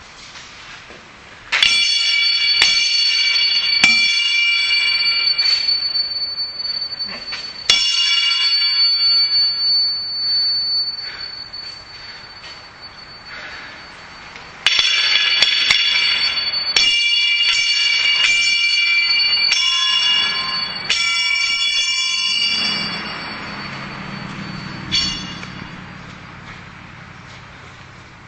自然 " 鸟鸣
描述：录音室里的鸟儿在唱歌。这是用FabFilter Twin 2制作的，是用我自己的补丁生成的，是在对Fab滤波器进行了一次实验后产生的。
标签： 唱歌 声音 啁啾 自然 天然 野生动物
声道立体声